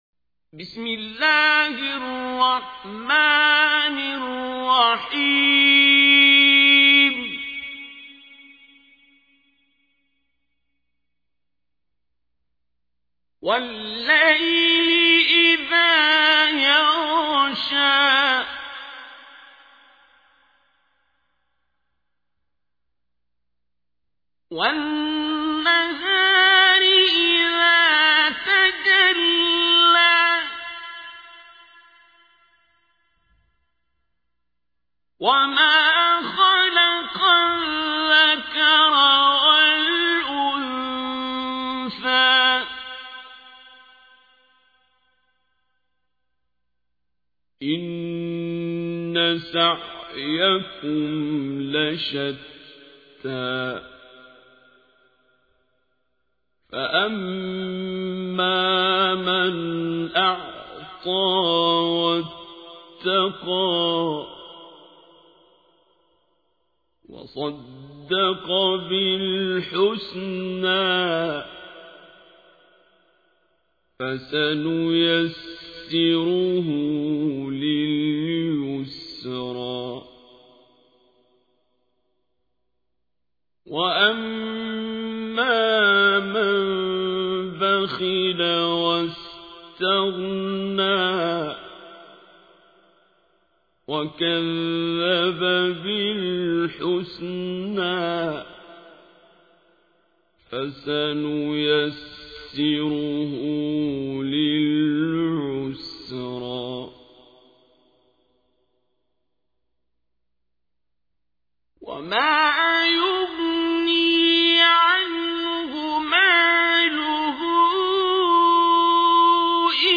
تحميل : 92. سورة الليل / القارئ عبد الباسط عبد الصمد / القرآن الكريم / موقع يا حسين